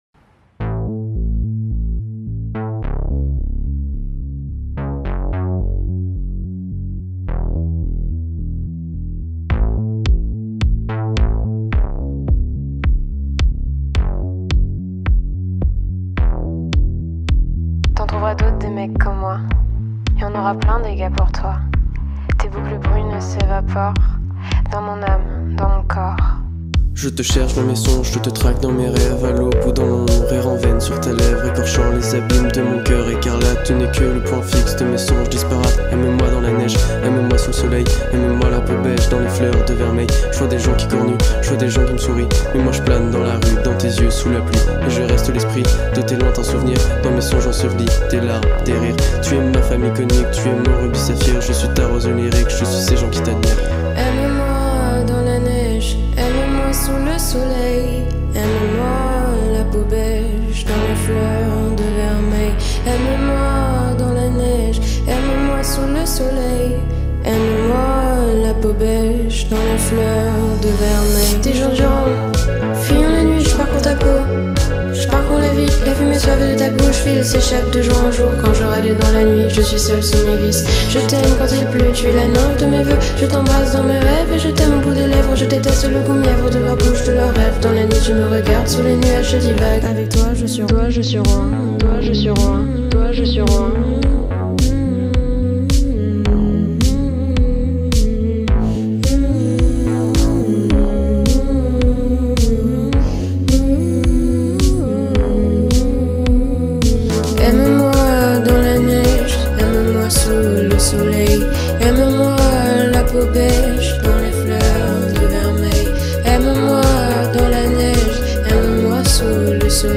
دانلود آهنگ فرانسوی
با ریتمی خاص و ارامش بخش
عاشقانه خارجی